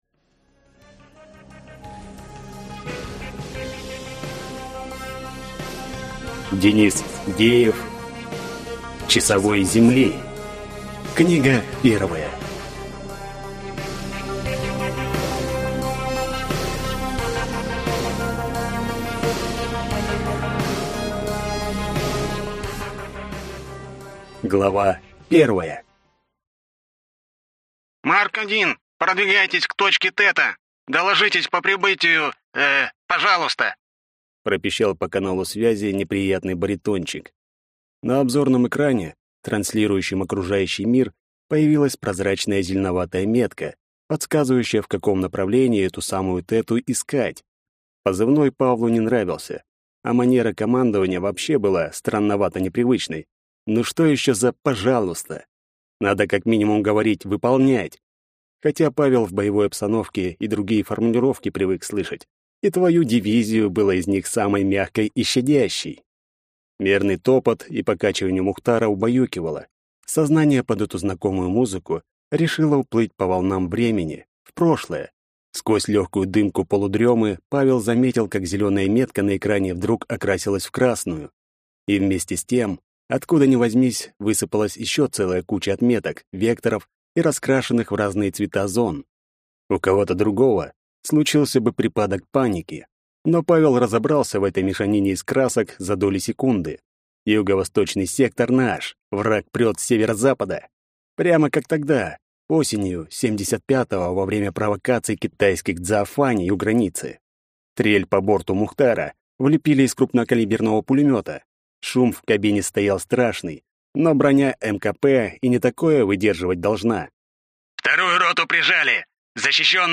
Аудиокнига Часовой Земли. Книга 1 | Библиотека аудиокниг